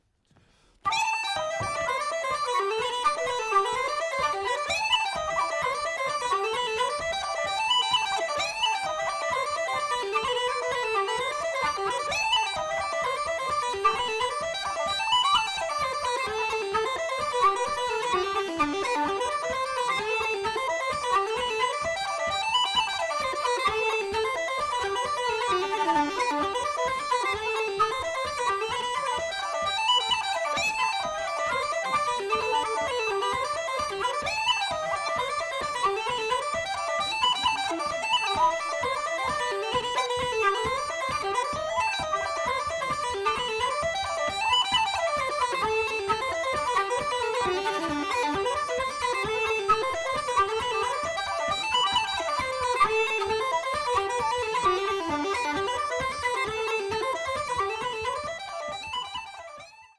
Reels 3.10